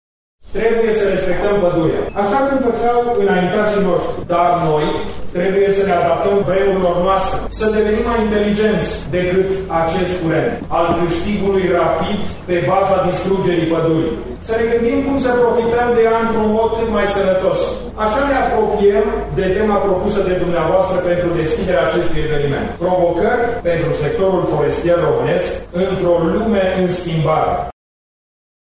De când în vestul europei preţul lemnului a devenit mult mai mic decât în România, tara noastră a început să şi importe, în fiecare an,  în jur de 5 milioane de metri cubi de lemn, spune ministrul Apelor şi Pădurilor, Ioan Deneş.